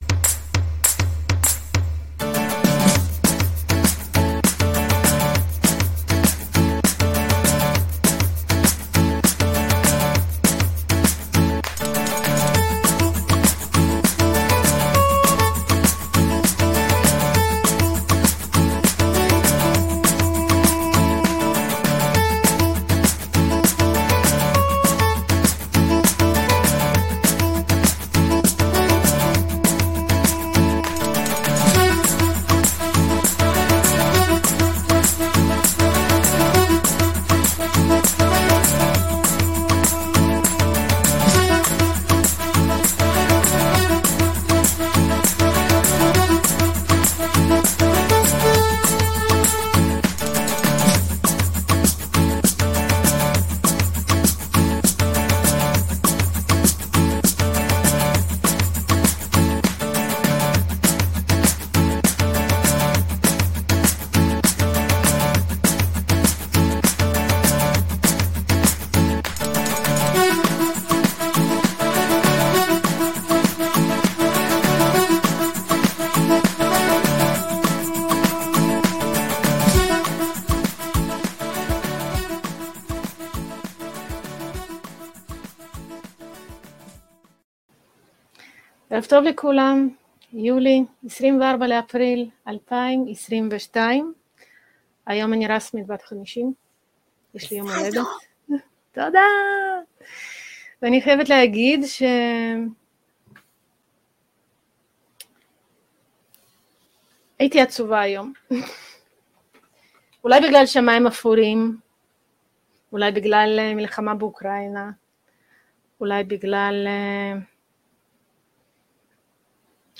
יום ג', 26/4, 12:00 בשידור היום אנחנו מדברות על עצב ואובדן בימינו, על והתמודדות בלחיות עם אנשים שעדיין במציאות מגבילה, ודברים נוספים... שידור חי בlive528 https